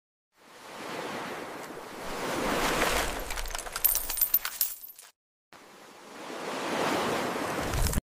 Satisfying ASMR videos of Dollar's sound effects free download
Satisfying ASMR videos of Dollar's and Gold coins